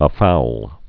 (ə-foul)